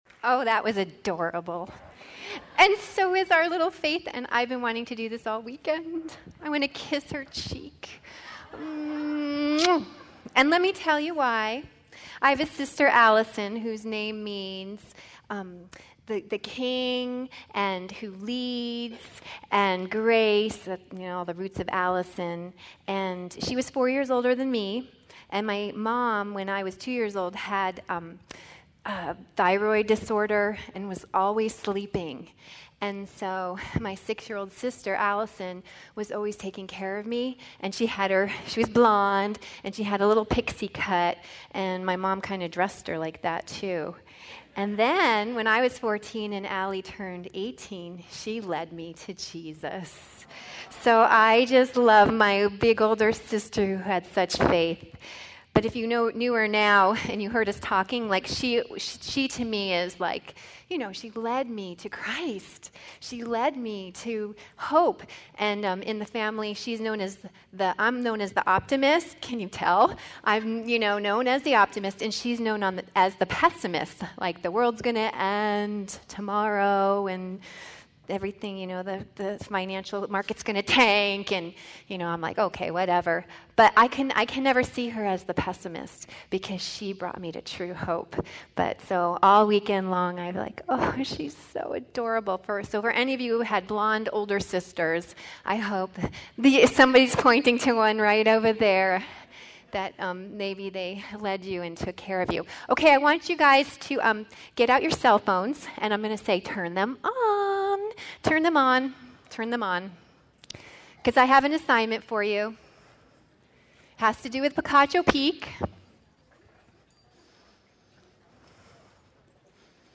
2012 Women's Retreat